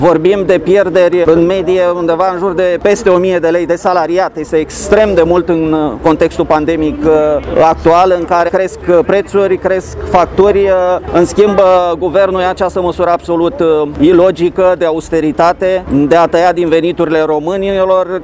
Zeci de sindicaliști au protestat astăzi în fața Prefecturii Mureș